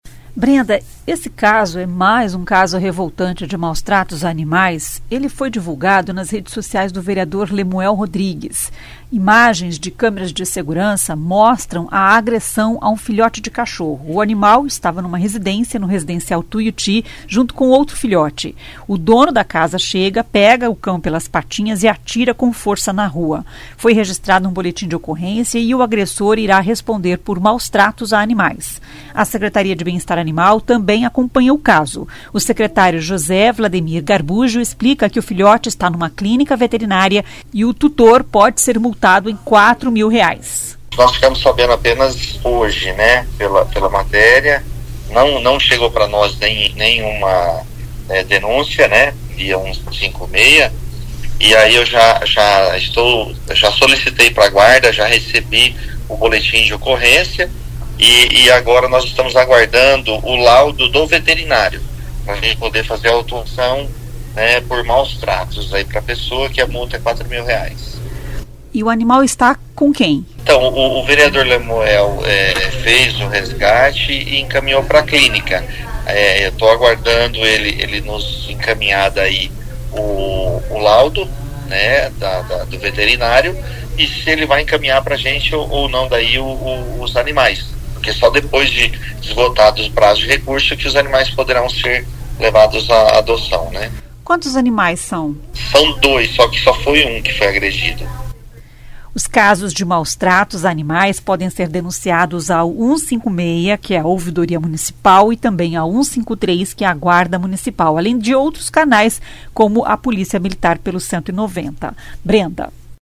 O secretário José Wlademir Garbuggio explica que o filhote está numa clínica veterinária e o tutor pode ser multado em R$ 4 mil.